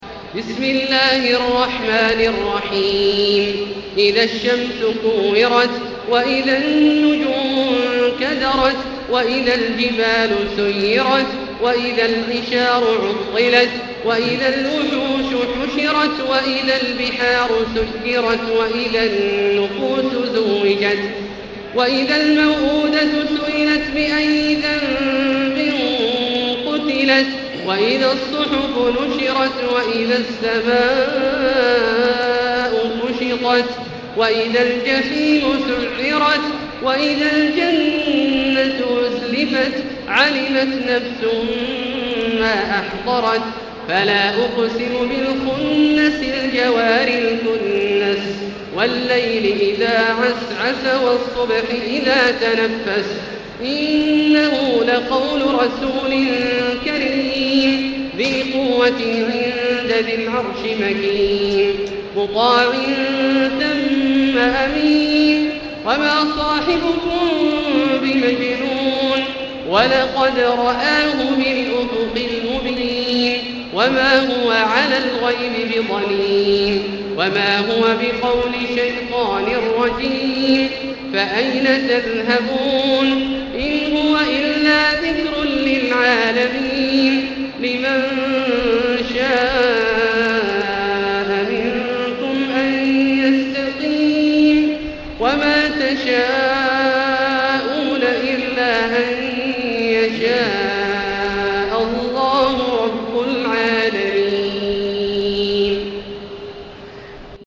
المكان: المسجد الحرام الشيخ: فضيلة الشيخ عبدالله الجهني فضيلة الشيخ عبدالله الجهني التكوير